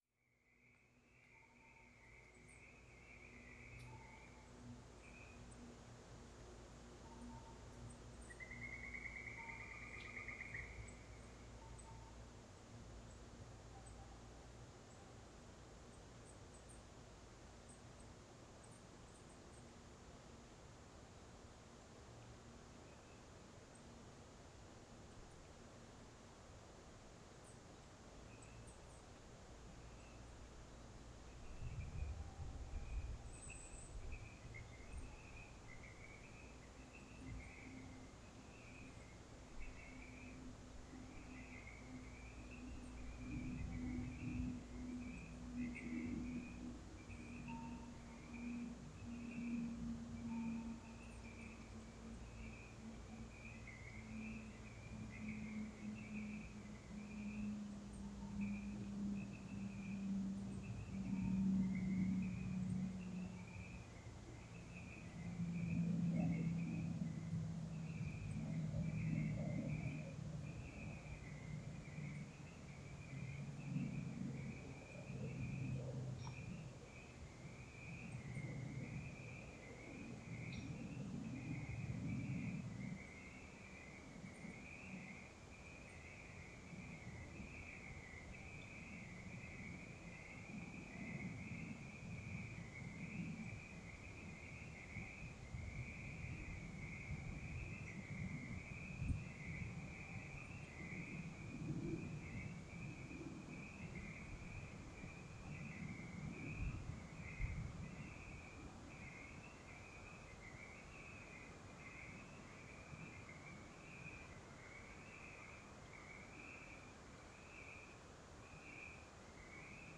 Recordings from the trail through the peaceful second growth forest at Hemer Provincial Park in early spring 2022.
2. Frog Chorus with Bird sounds – Flicker, Hairy/Downy Woodpecker, Geese, Robin – finishes with slightly creepy sound. Tree creaking? Frogs? (also there’s a plane)